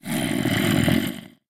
Minecraft Version Minecraft Version snapshot Latest Release | Latest Snapshot snapshot / assets / minecraft / sounds / mob / zombie / say1.ogg Compare With Compare With Latest Release | Latest Snapshot